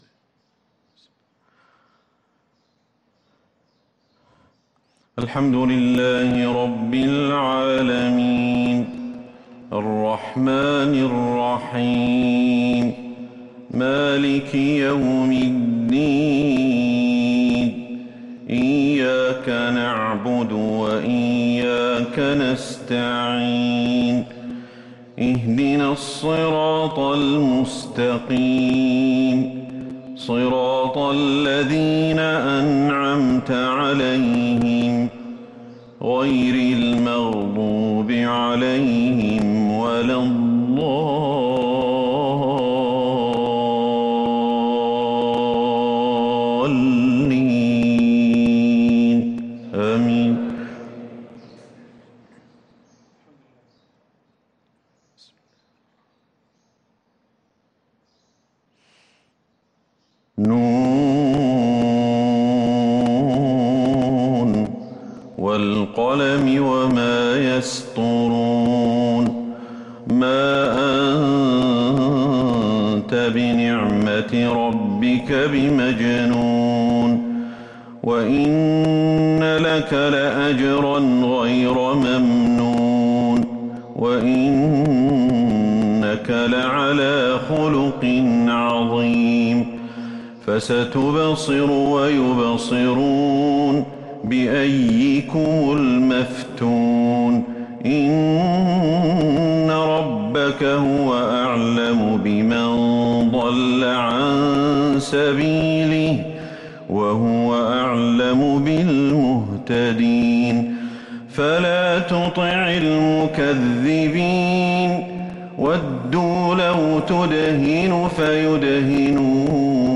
فجر الثلاثاء 9 محرم 1443 هـ سورة {القلم} > 1443 هـ > الفروض